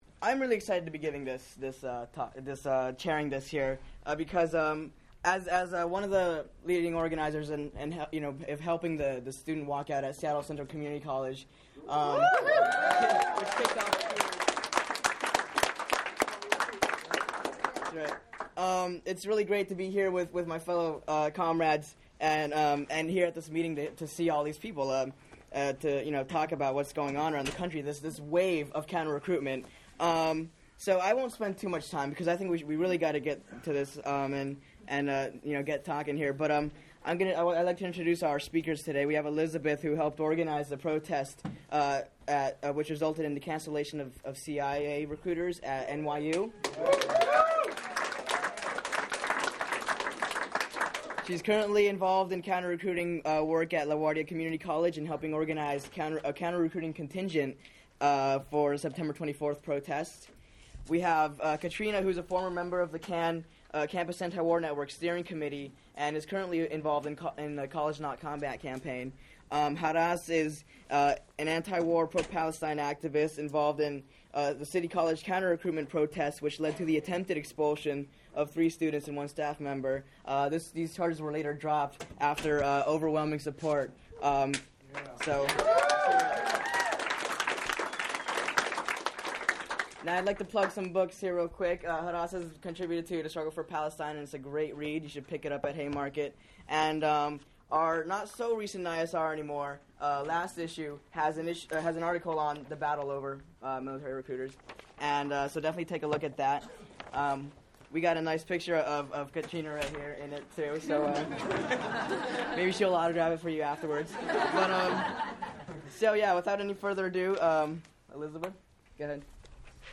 Campus Antiwar Network on Counter-Recruitment CAN workshop at Socialism 2005 , Chicago, July 3, 2005
This workshop - given by three leading student organizers in the US - led to many discussions on how to get military recruiters off both high school and college campuses.